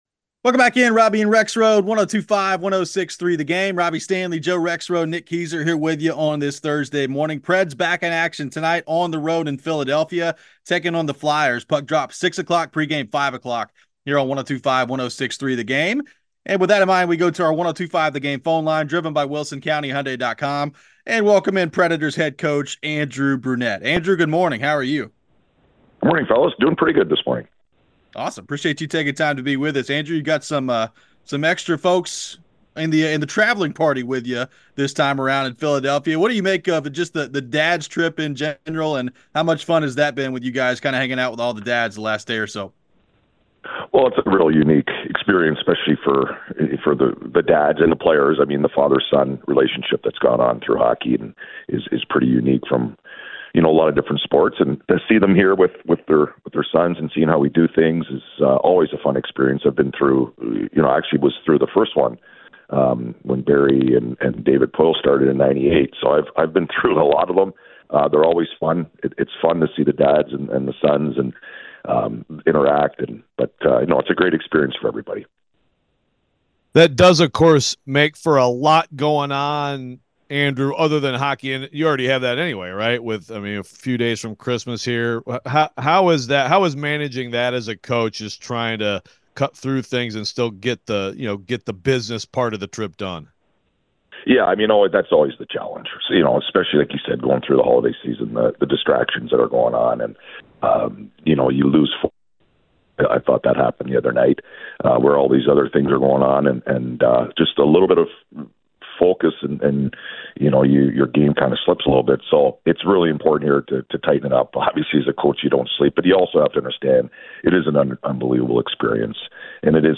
Andrew Brunette Interview (12-21-23)